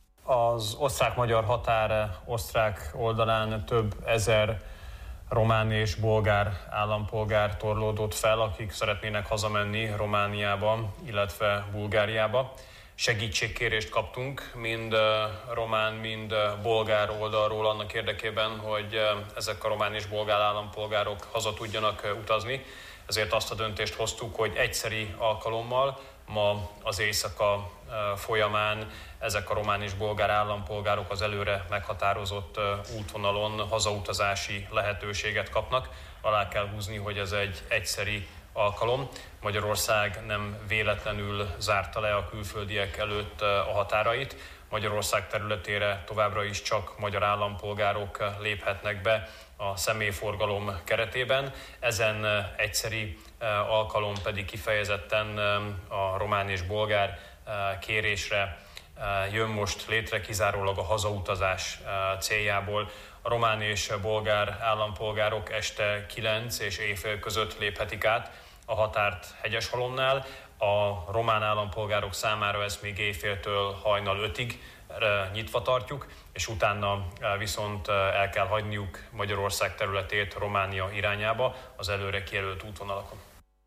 Magyarország humanitárius folyosót nyit annak érdekében, hogy hazatérhessenek Romániába az osztrák-magyar határon veszteglő román állampolgárok – jelentette ki a Marosvásárhelyi Rádiónak adott interjúban Szijjártó Péter.
A magyar külgazdasági és külügyminiszter hangsúlyozta, Magyarország egyszeri alkalommal, kivételes intézkedésként hozta meg a döntést, a határok ezután is zárva maradnak, a magyar állampolgárok biztonsága érdekében. Szijjártó Pétert hallják.